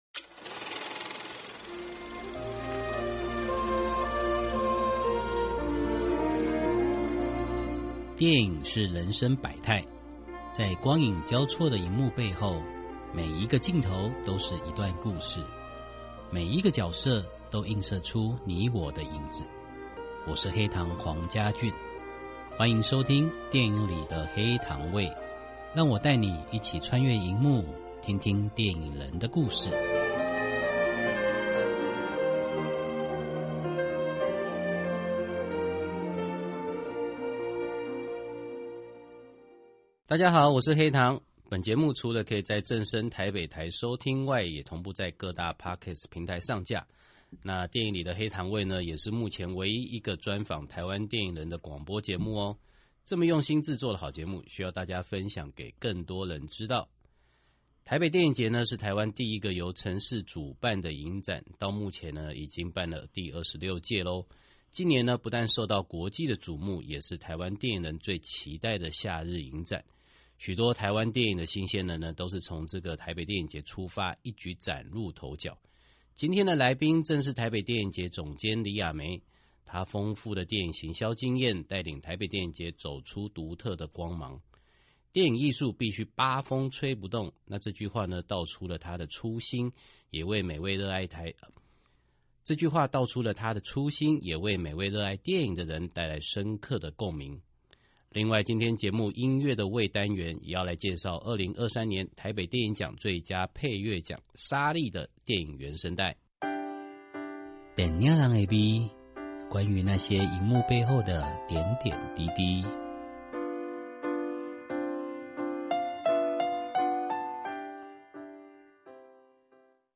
訪問大綱： 1.分享童年在屏東二輪電影院的經歷，如何培養對電影的熱情。